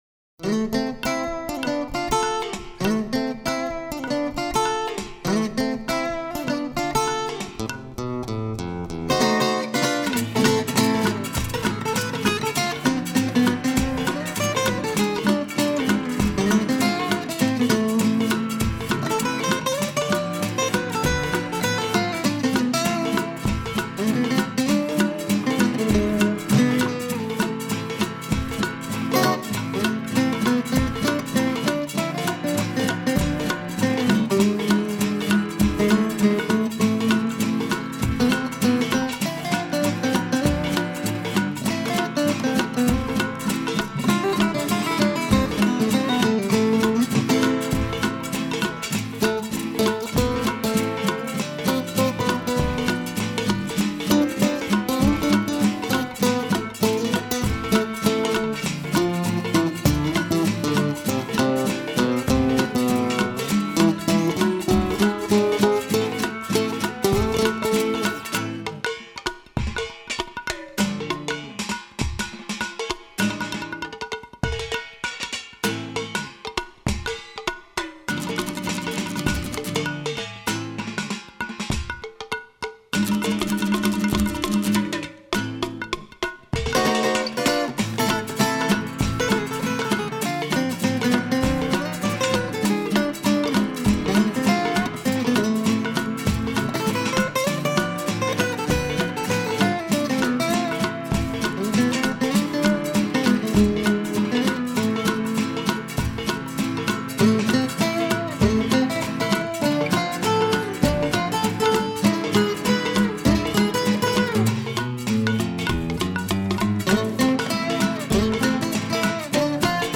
Soundtrack, Orchestral